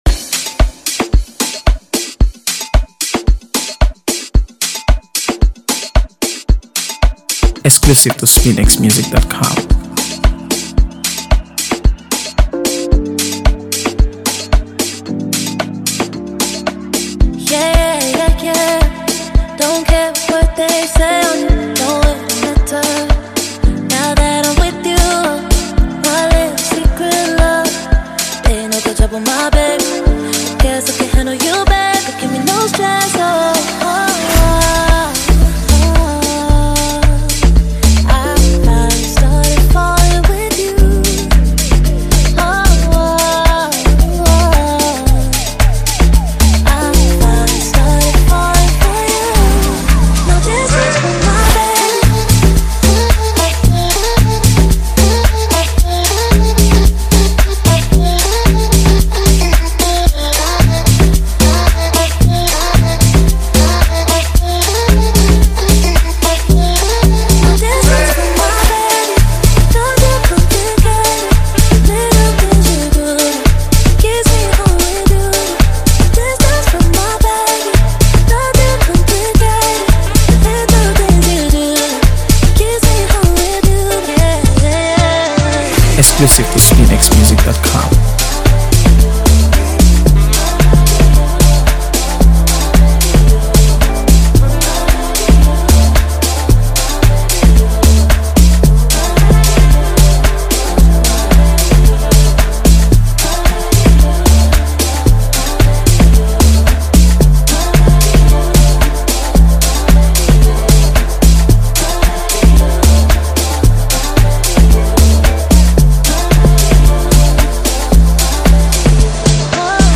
AfroBeats | AfroBeats songs
smooth and soulful vocals